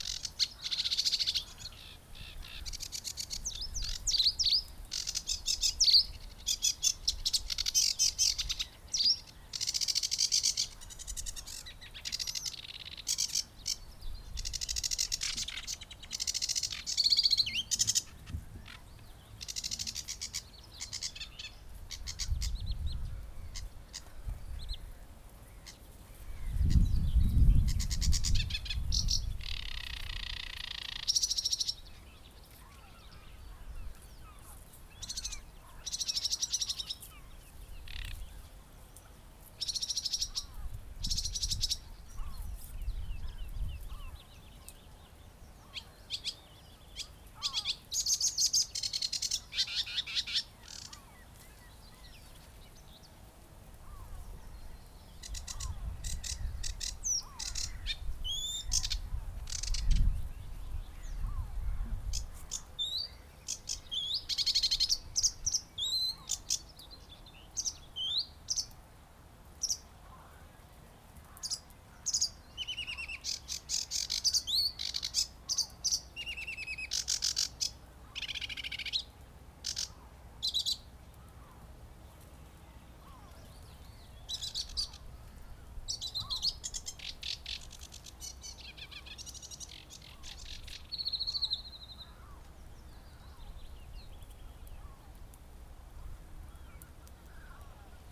Hääli ja pilte roostikust
Kõrkja-roolind       Acrocephalus schoenobaenus
Kõrkja-roolind ja teised rannaelanikud 10. mail kella kaheksa paiku
Ümberringi lendavad kõrkja-roolinnud ja õiendavad omavahel maid.
Vaadet merele katab paks roosein ja eemalt kostub hüübi pudelikaela puhumist meenutav hääl.
kühmnokk-luige tiivalöök ning pea kohal lendavad kajakad ja liugleb suitsupääsukene.